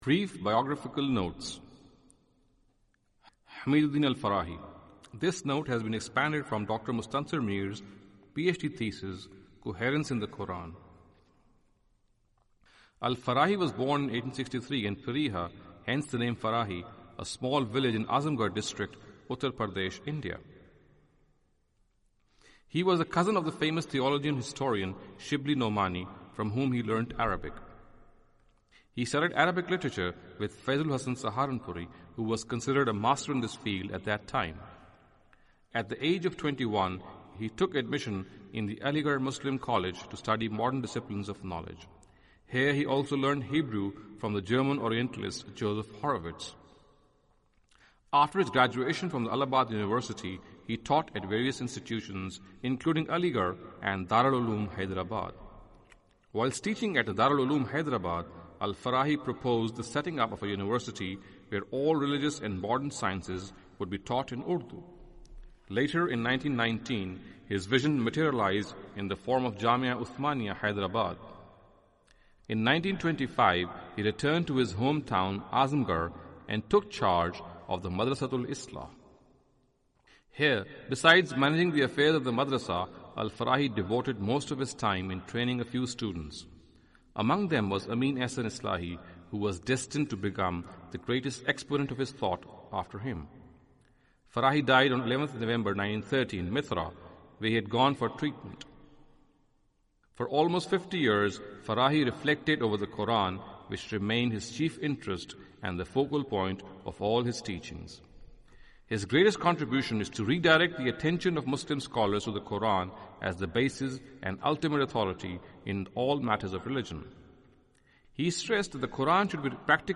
Audio book of English translation of Javed Ahmad Ghamidi's book "Playing God".